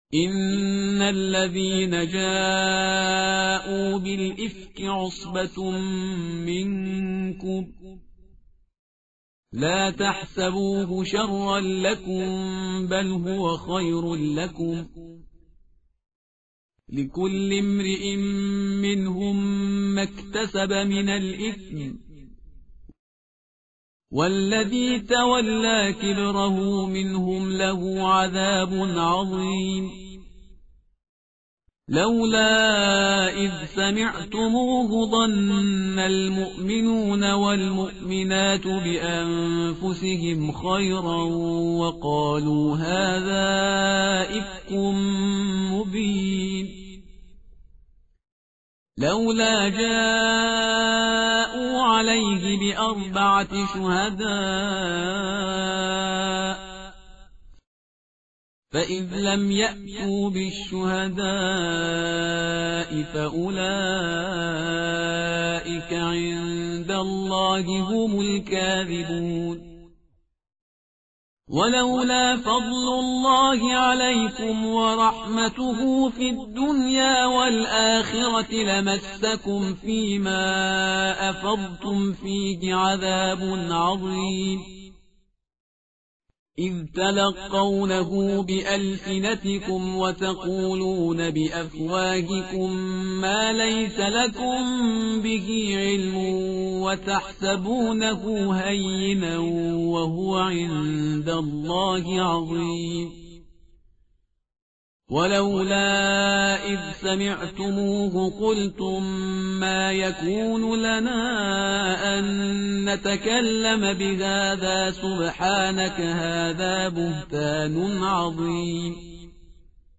متن قرآن همراه باتلاوت قرآن و ترجمه
tartil_parhizgar_page_351.mp3